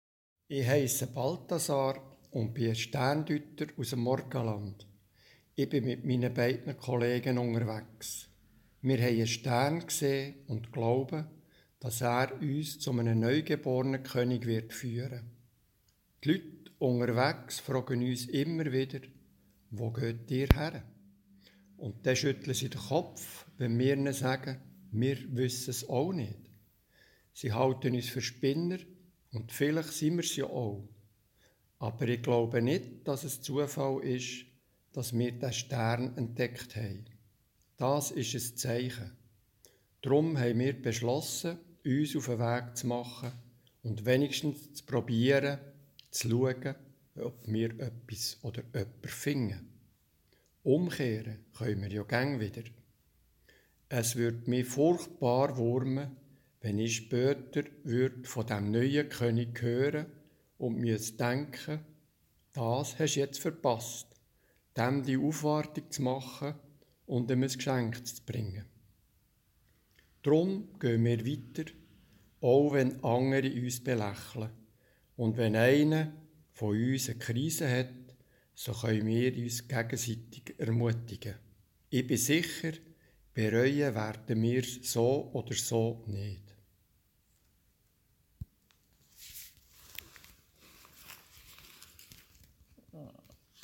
In der festlich geschmückten Kirche am Heiligabend waren nebst Gesang und Orgel auch Stimmen verschiedener Personen zu hören. Auf Aussagen von Figuren aus der Weihnachtsgeschichte reagierten Menschen von heute mit ihren Gedanken.